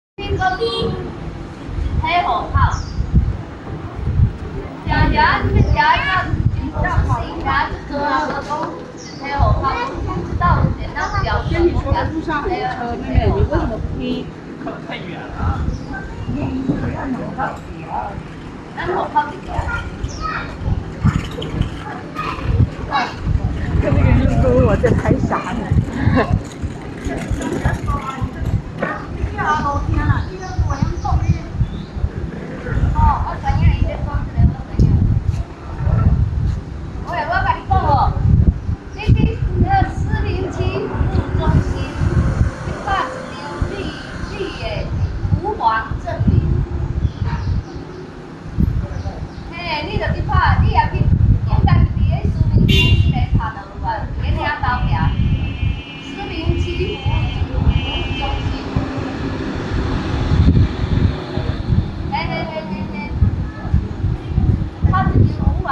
The recording depicts a group of elderly locals from Xiamen engaging in conversation. As modernization continues to progress, an increasing number of young people are leaving the city to pursue employment prospects elsewhere.